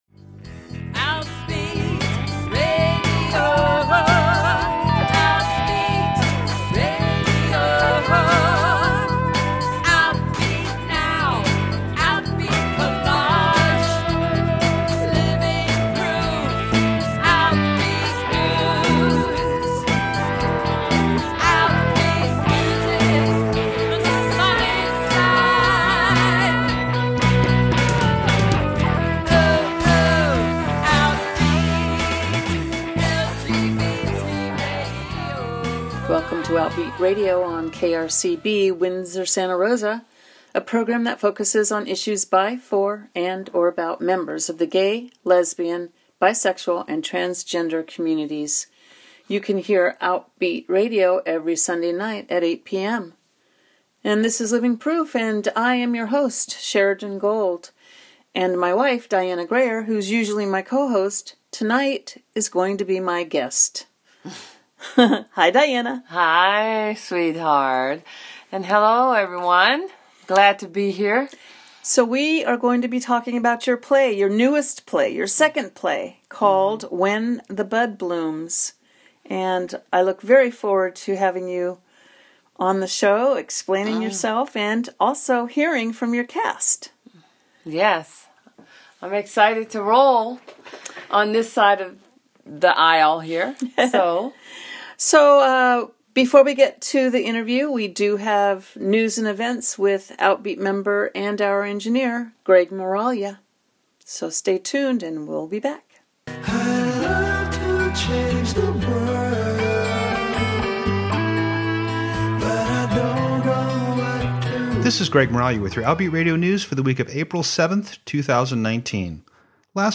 Outbeat Radio is a weekly radio program for and about the lesbian, gay, bisexual and transgender community in the California North Bay airing on KRCB Radio 91.1 FM
Also, as an added bonus, hear from her cast!
Good show, nice pacing, interesting people and worth while listening.